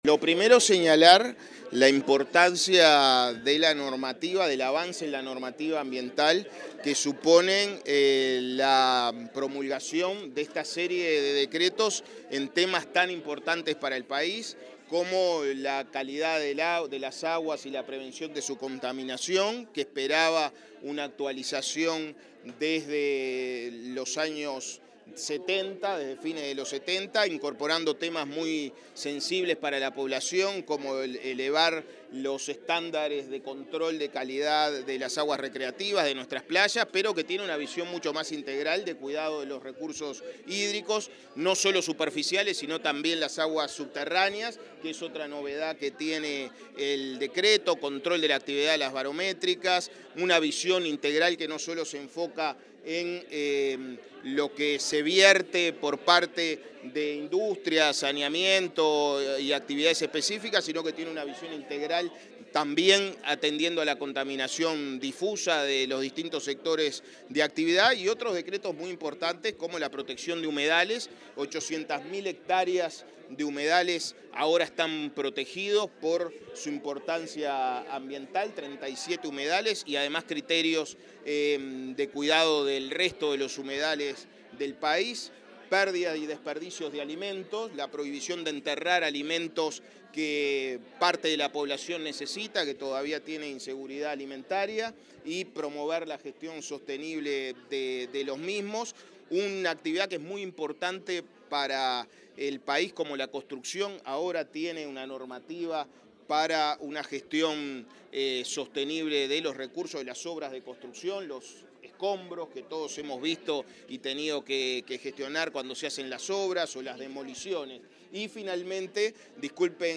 Declaraciones del ministro de Ambiente, Edgardo Ortuño